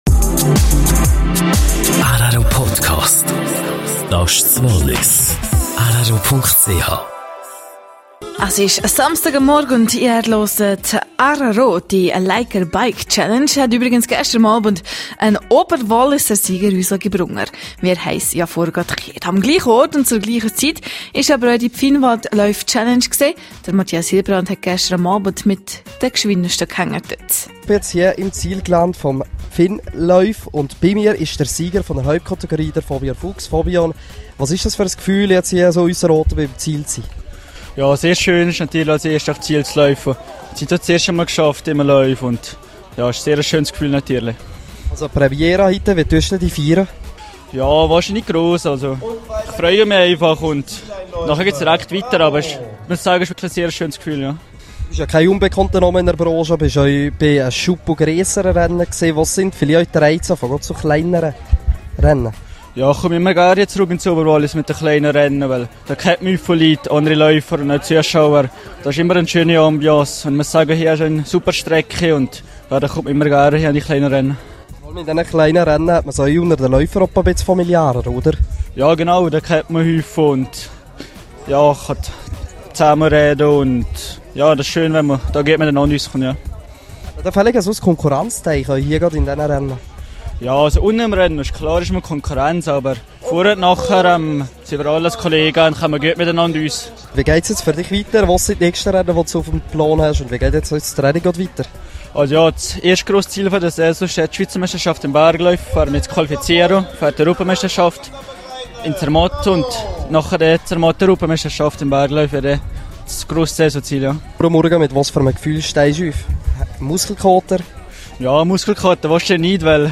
26302_News.mp3